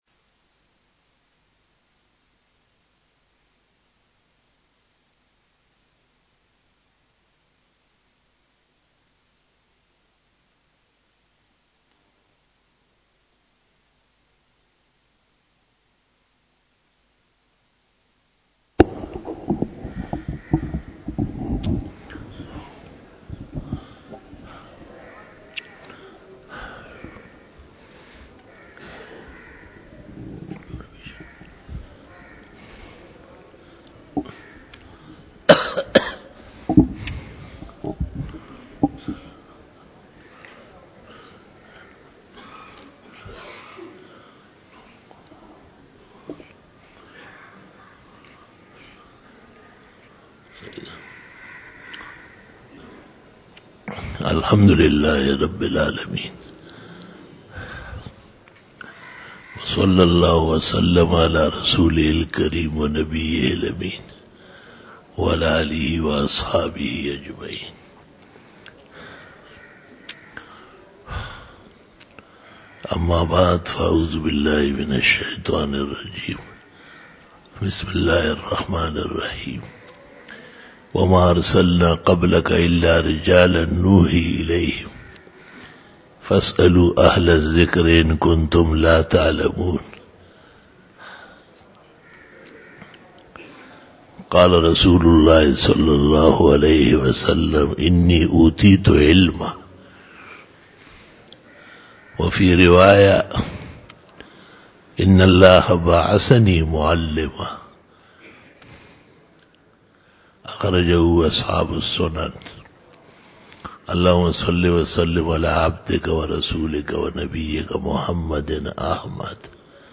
52 BAYAN E JUMA TUL MUBARAK (28 December 2018) (20 Rabi us Sani 1440H)